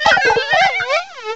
cry_not_combee.aif